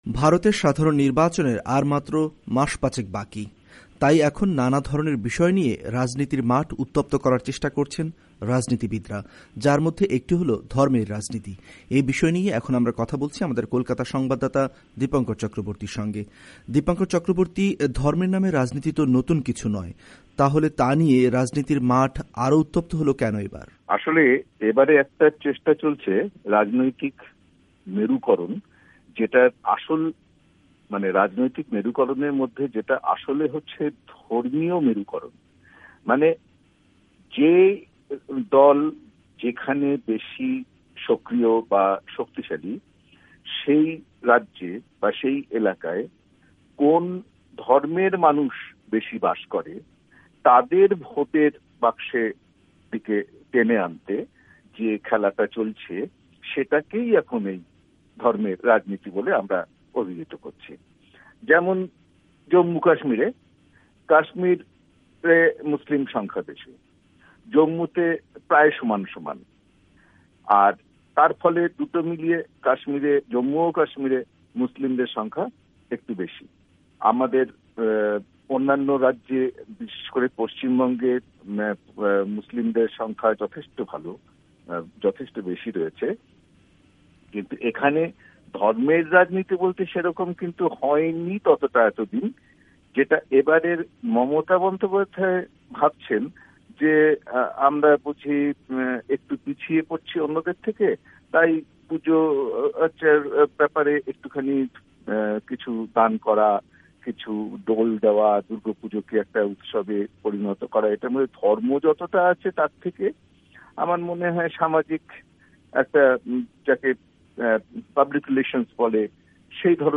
ওয়াশিংটন স্টুডিও থেকে কথা বলেছেন